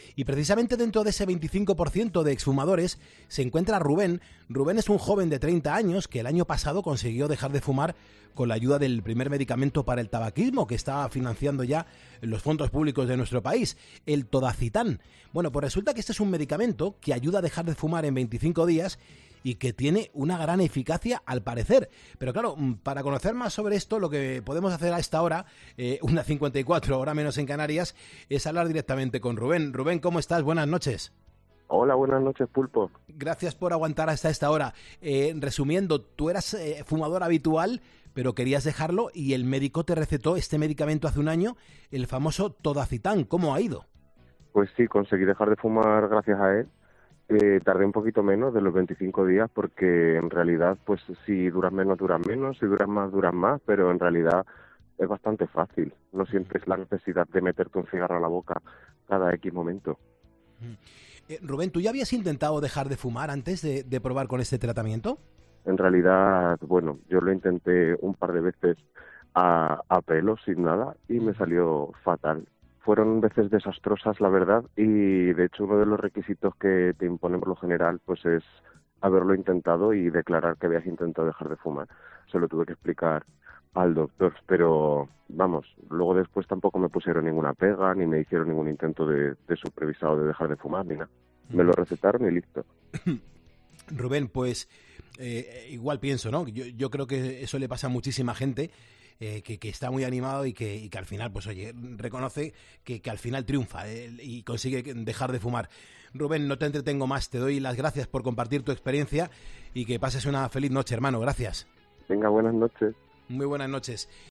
¿Dejar de fumar tabaco con el medicamento financiado por el Gobierno? Un joven de 30 años lo prueba